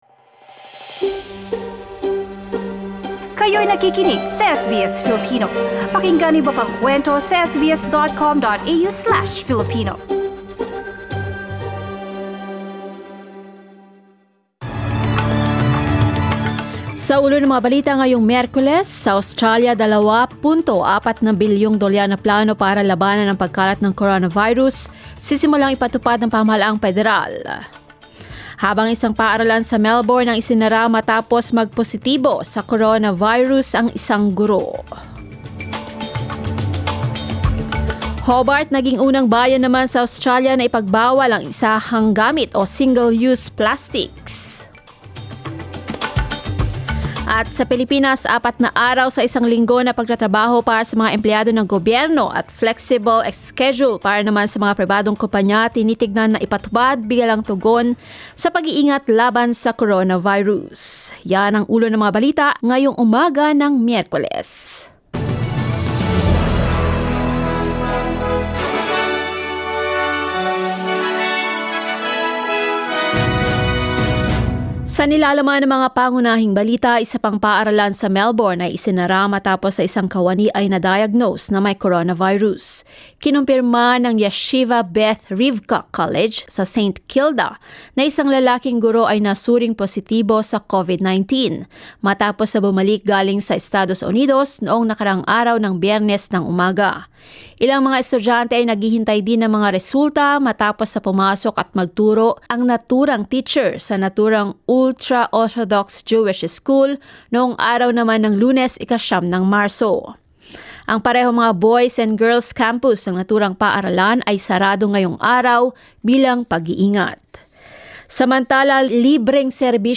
SBS News in Filipino, Wednesday 11 March